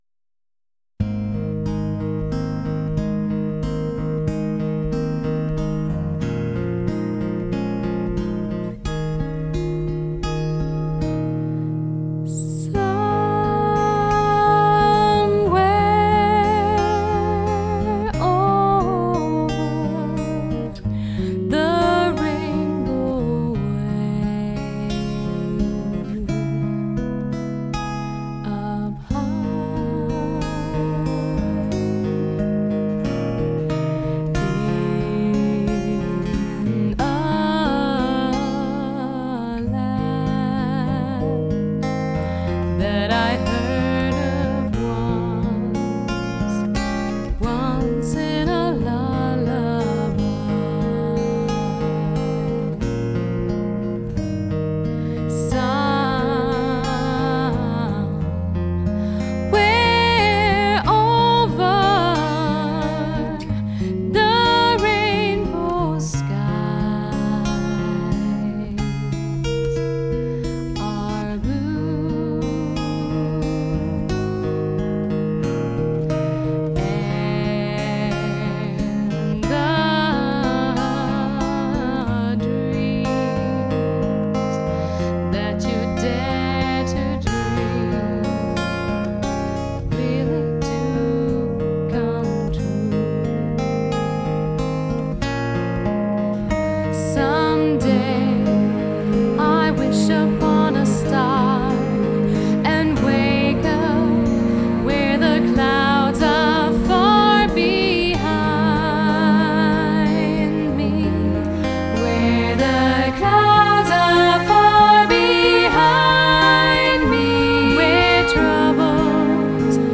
gefühlvollen und extrem wandlungsfähigen Stimme
Sie überzeugt sowohl a cappella als auch in Soloperformances mit hochqualitativen Instrumentalbegleitungen.